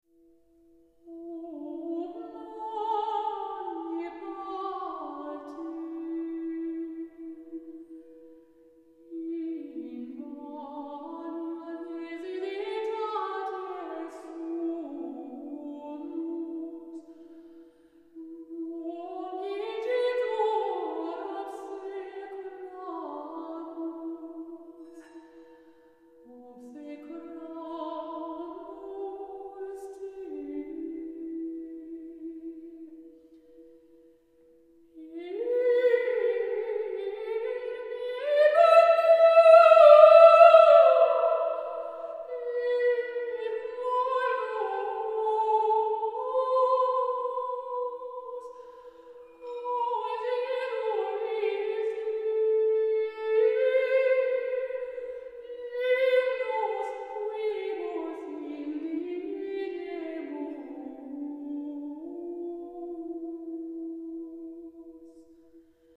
Texts and Translations of Hildegard von Bingen's Gregorian Chants